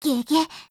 贡献 ） 协议：Copyright，其他分类： 分类:语音 、 分类:少女前线:P2000 您不可以覆盖此文件。